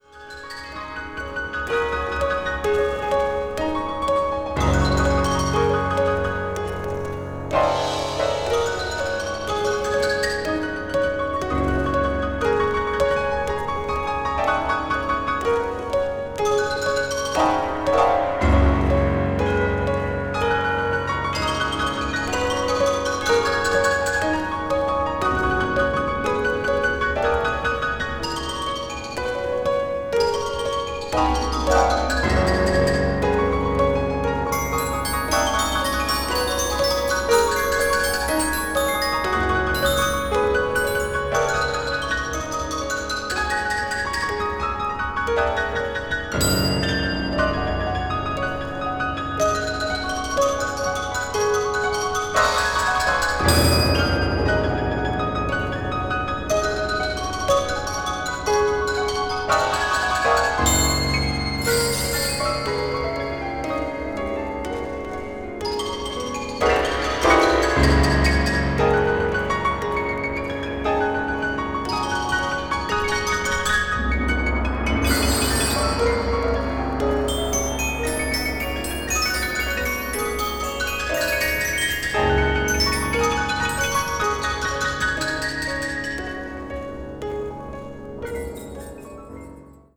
media : EX/EX(わずかにチリノイズが入る箇所あり)
20th century   contemporary   modern classical   post modern